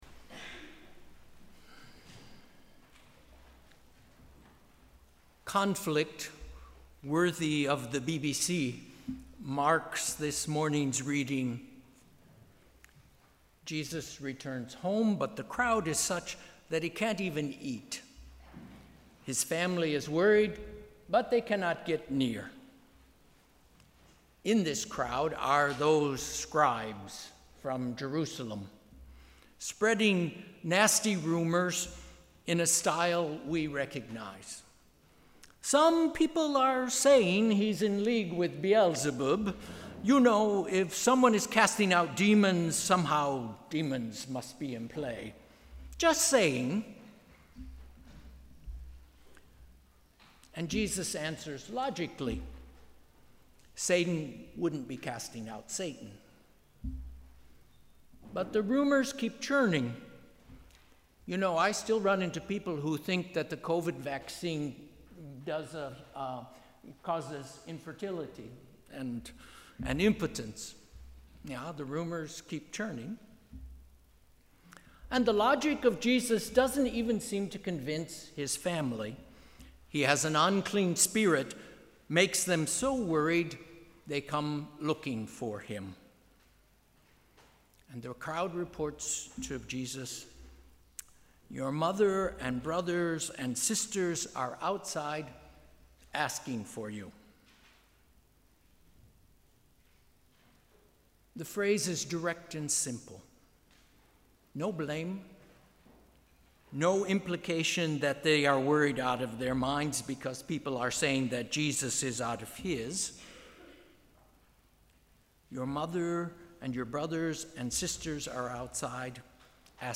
Sermon: ‘Who is my sister?’
Third Sunday after Pentecost